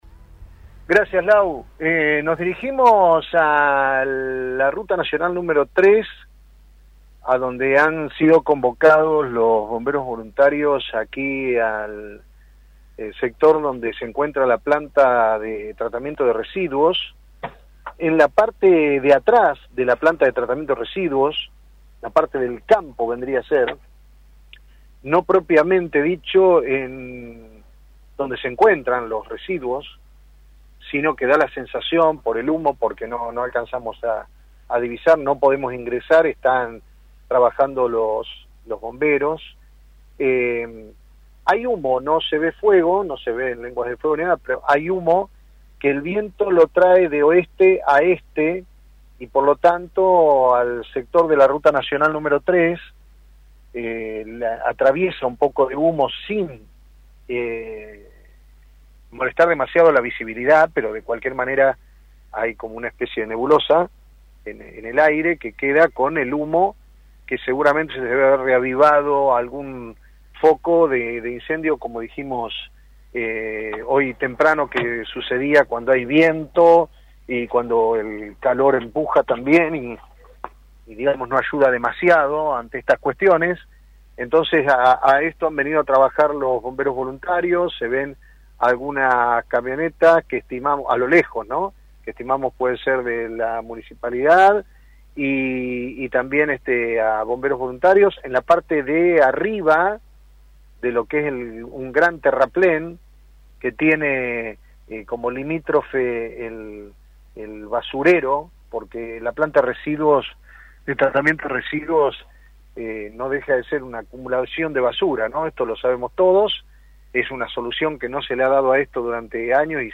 Informe de Play Radios desde el lugar: